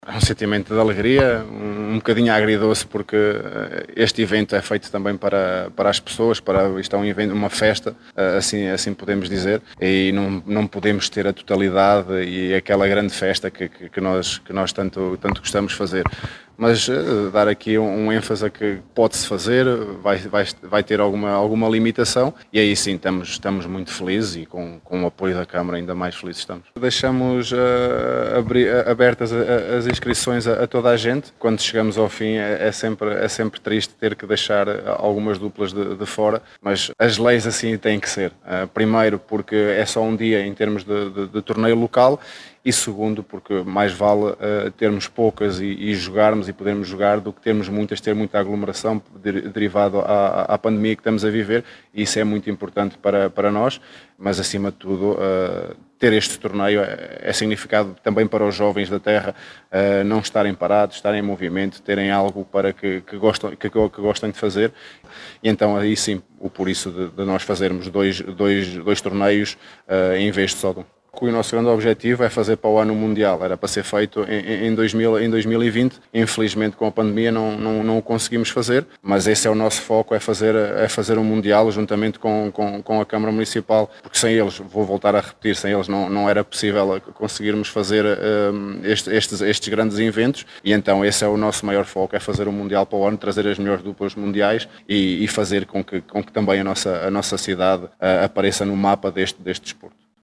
Já Hugo Almeida mostrou-se feliz pela realização deste evento mas, lamenta que haja limitação de público e de participantes devido às regras sanitárias: